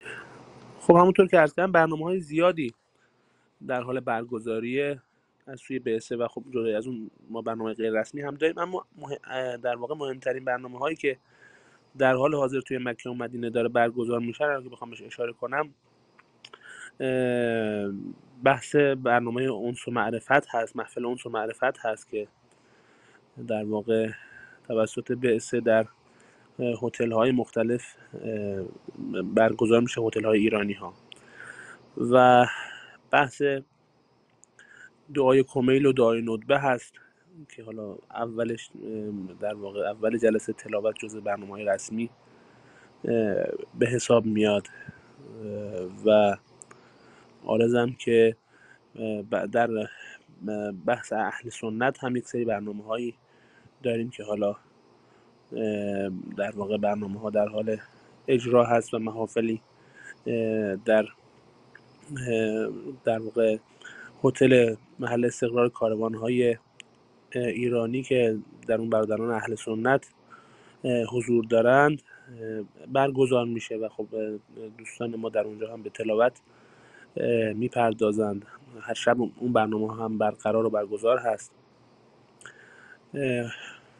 Рӯзҳои пурҷушу хуруши корвони Қуръонии Ҳаҷ; Зоирон аз қироати қориёни эронӣ истиқбол карданд + садо
Тегҳо: Корвон ، Қориён ، Тиловати Қуръон